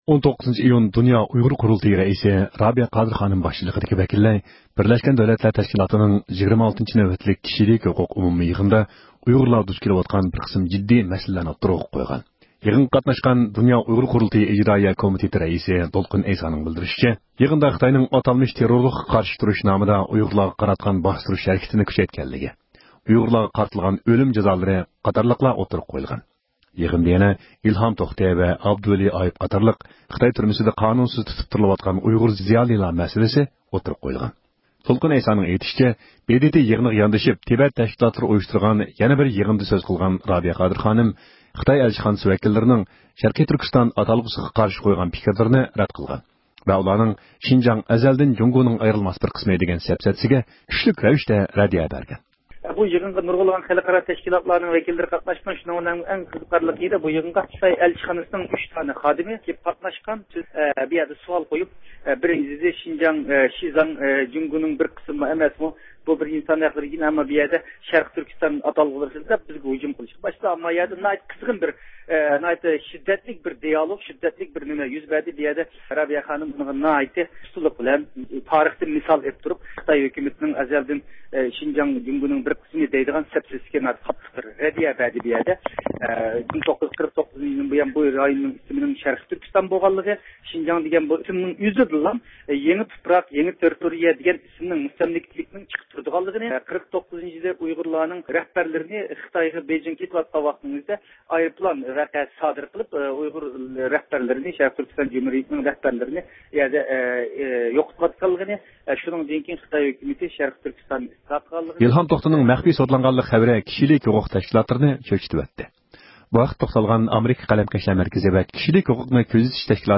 ھەپتىلىك خەۋەرلەر (14-ئىيۇندىن 20-ئىيۇنغىچە) – ئۇيغۇر مىللى ھەركىتى